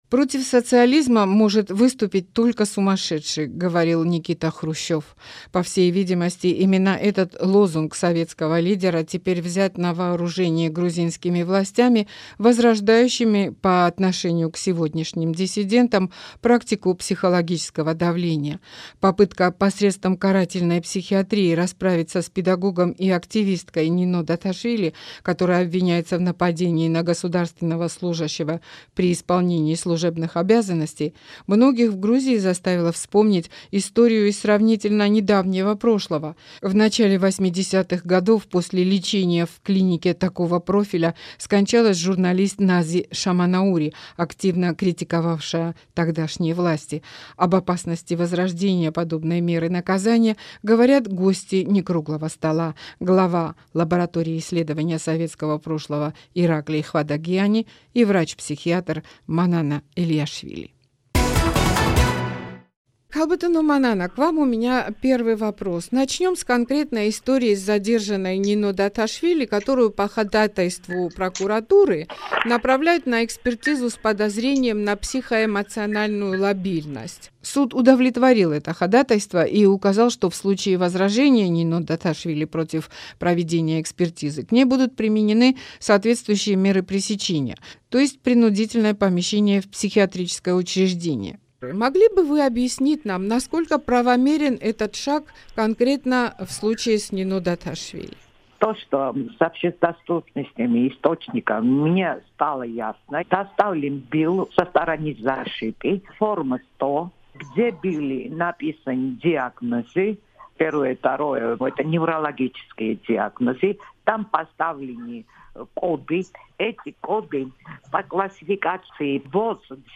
Рубрика Некруглый стол, разговор с экспертами на самые актуальные темы